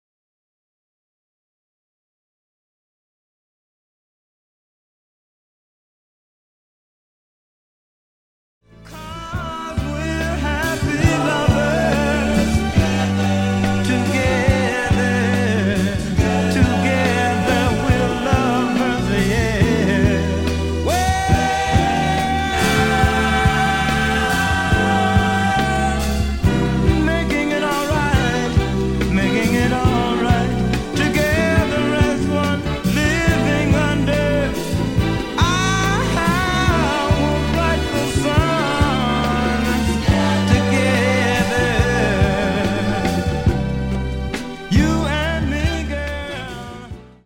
rare Up-tempo modern soul on 7".